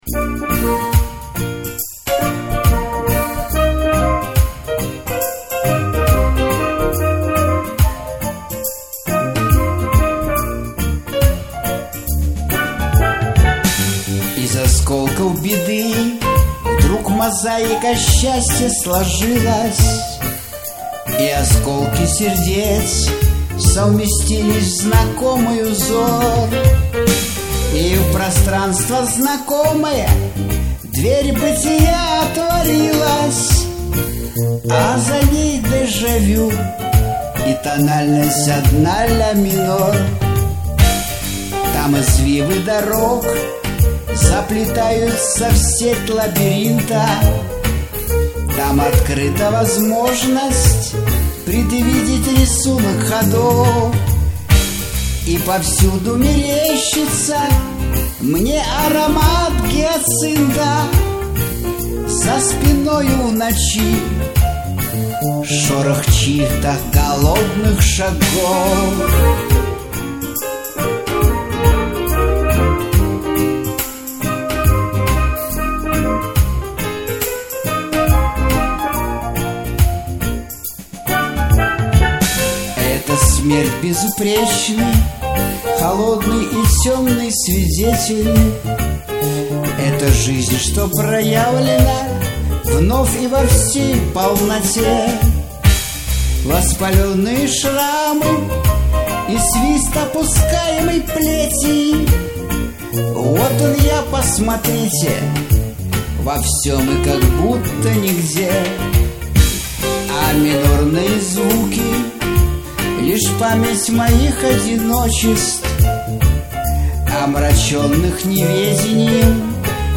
Романтическая музыка